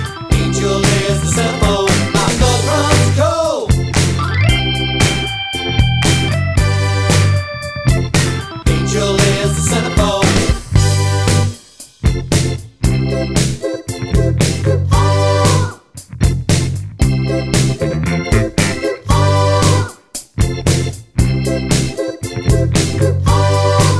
Minus All Guitars Pop (1980s) 3:34 Buy £1.50